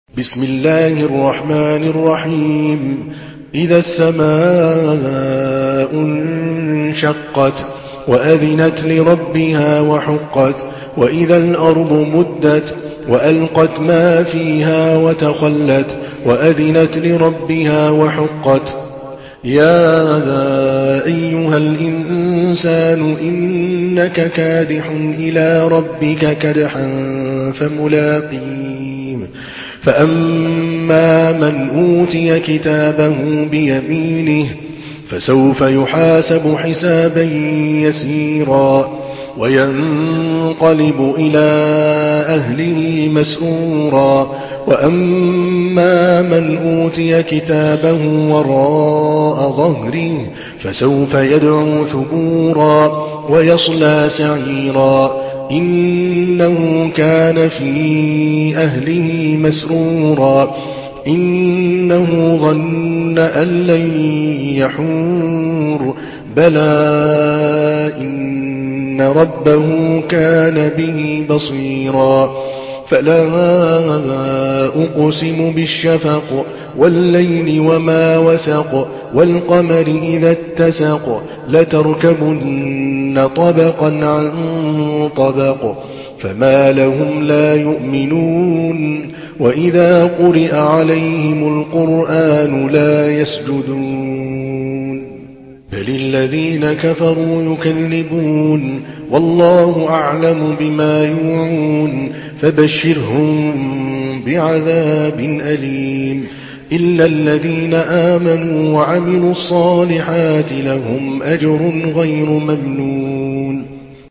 سورة الانشقاق MP3 بصوت عادل الكلباني برواية حفص
مرتل